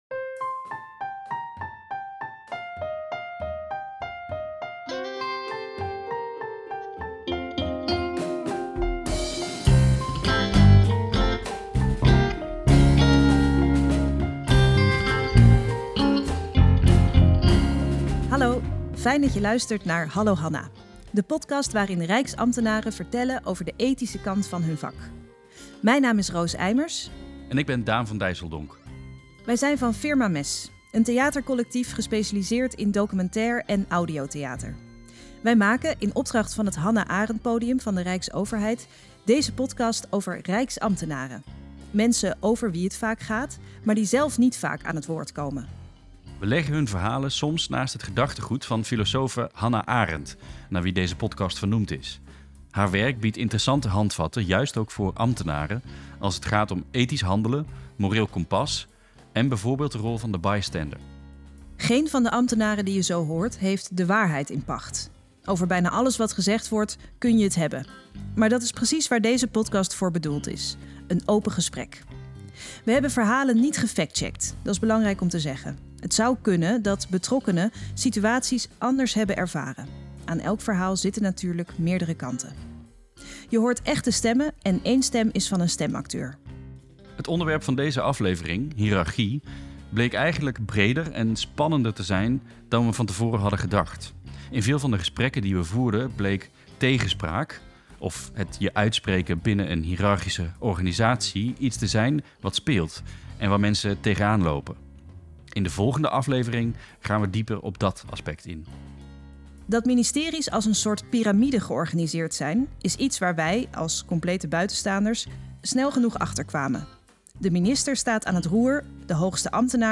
Het Hannah Arendt Podium wil reflectie en dialoog stimuleren over morele vragen in het werk van rijksambtenaren. In de podcast vertellen Rijksambtenaren over situaties die zij als ingewikkeld of bepalend hebben ervaren. Hun verhalen worden geplaatst in de context van het werk van Hannah Arendt over denken, handelen en oordelen.